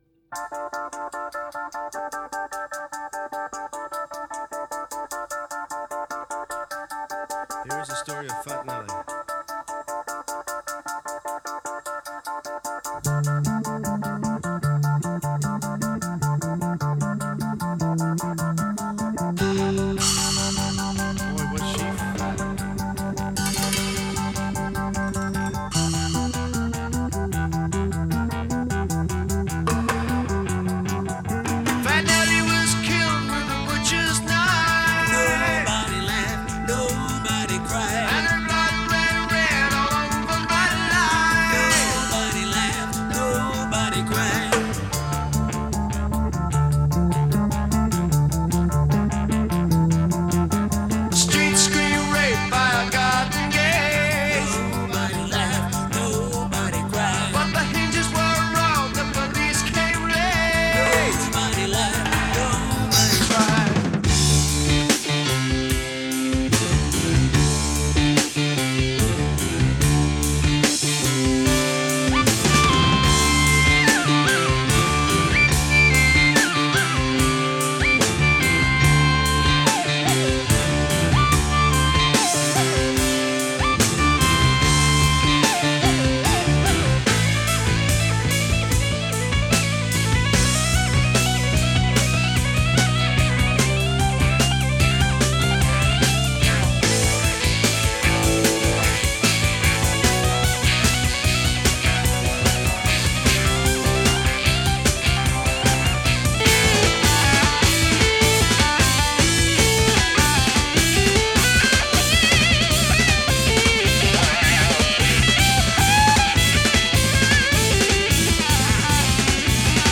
рок-группы